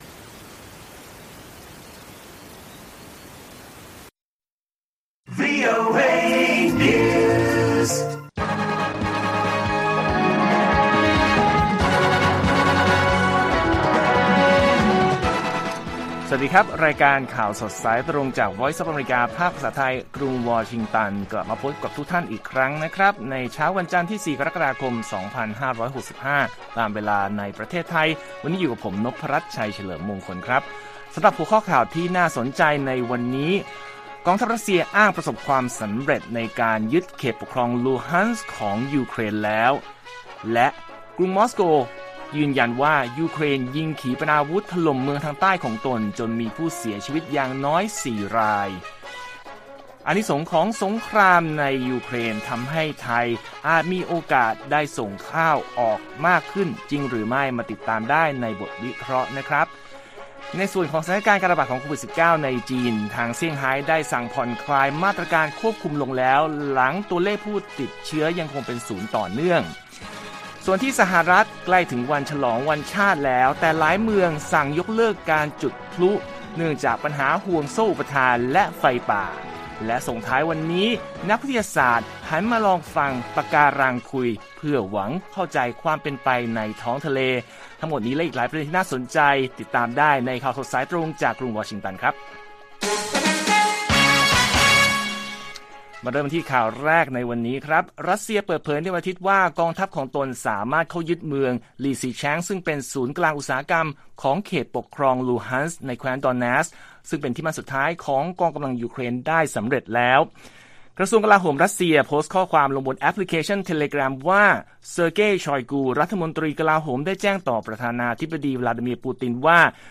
ข่าวสดสายตรงจากวีโอเอไทย วันจันทร์ 4 ก.ค. 2565